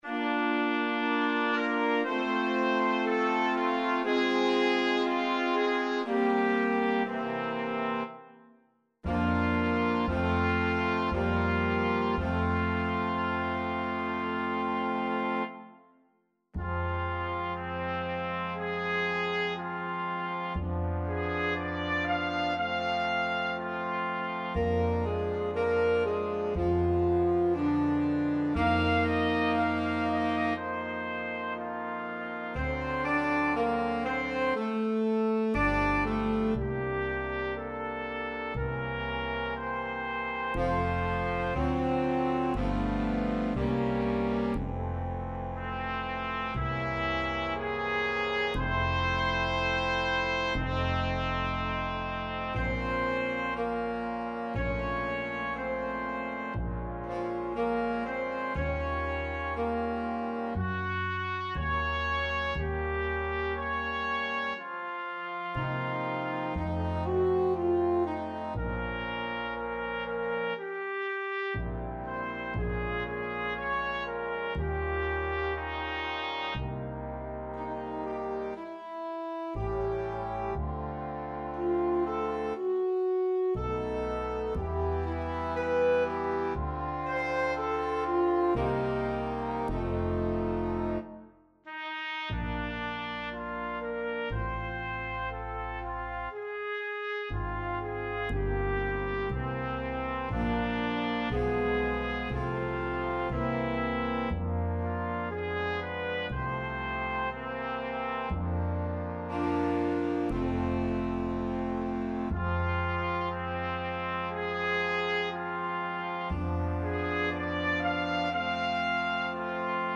Skladby pro Big Band / Big Band Scores
computer demo